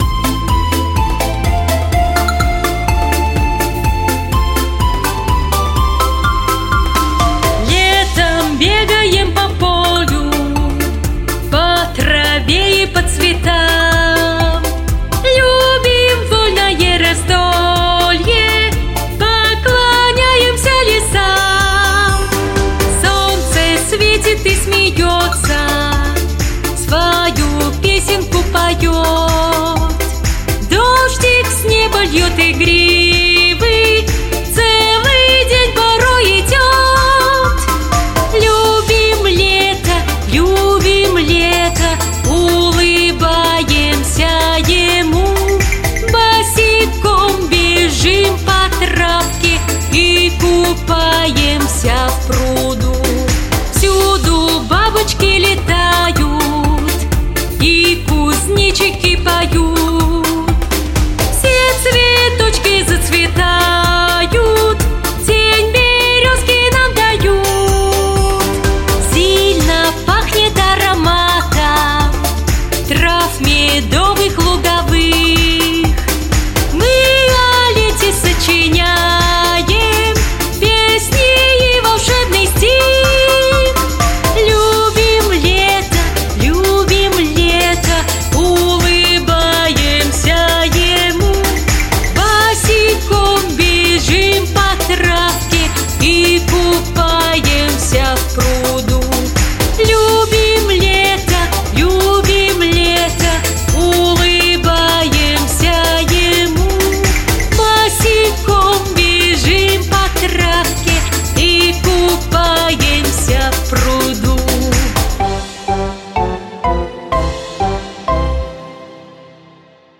Современные, новые, популярные песни для детей 👶👧